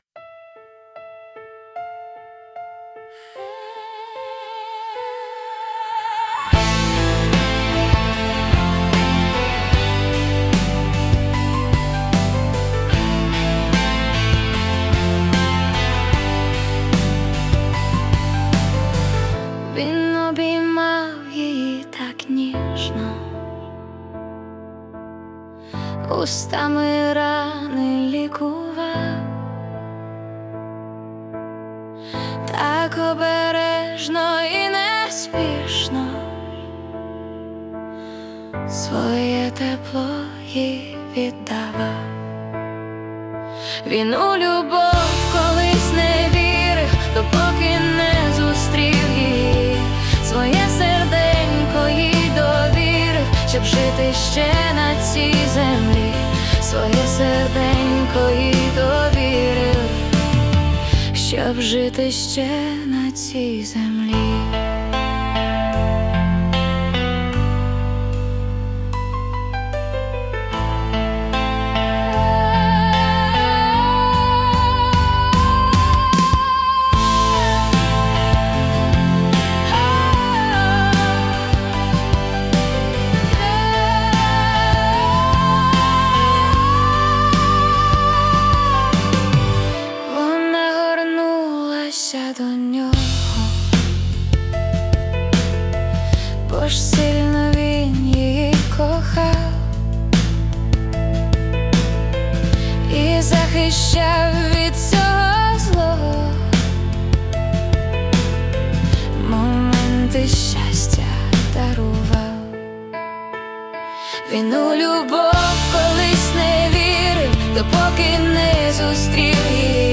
Музика та виконання ШІ
СТИЛЬОВІ ЖАНРИ: Ліричний